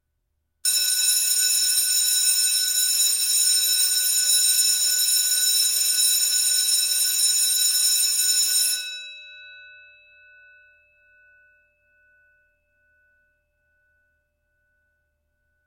Schulglocke Klingelton
Der Schulglocken-Klingelton erinnert an den Beginn des Unterrichts und bringt die Atmosphäre der Schule direkt auf Ihr Handy.
schulglocke-klingelton-de-www_tiengdong_com.mp3